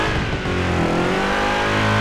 Index of /server/sound/vehicles/sgmcars/buggy